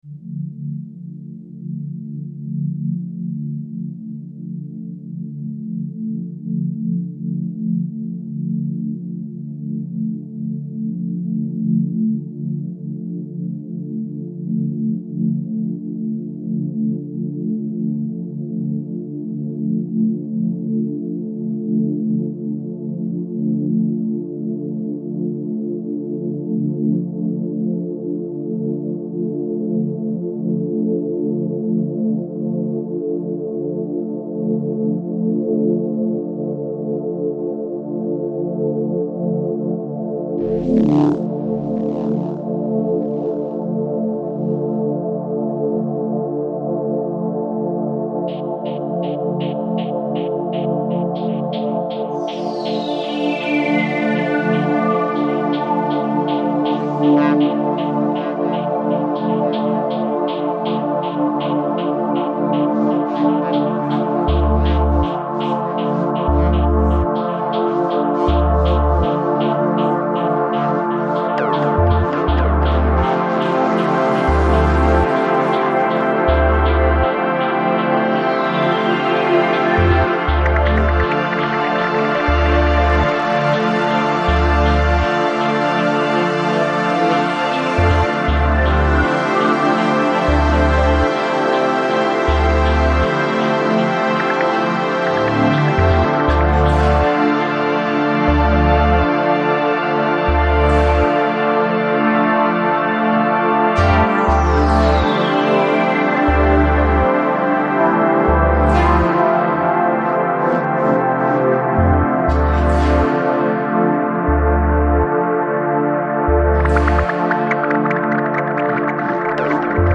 Жанр: Electronica, Downtempo